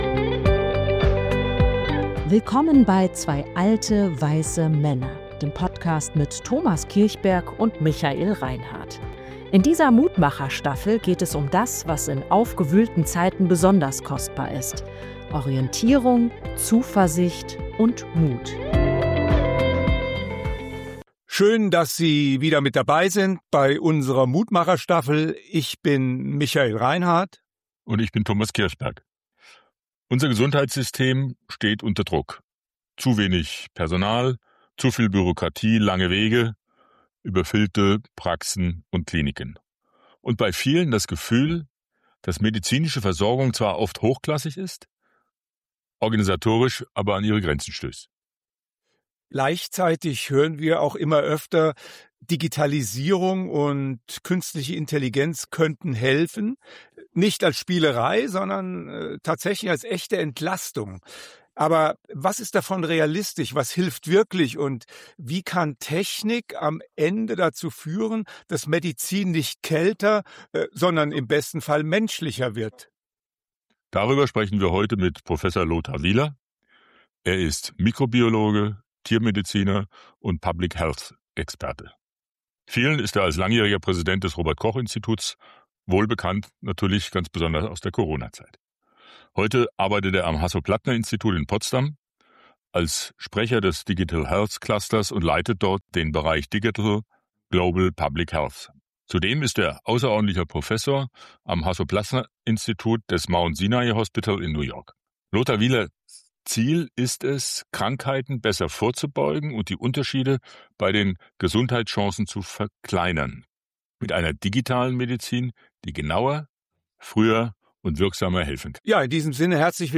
In dieser Folge sprechen wir mit Prof. Dr. Lothar Wieler, Mikrobiologe, Tiermediziner und Public-Health-Experte, vielen bekannt als langjähriger Präsident des Robert Koch-Instituts.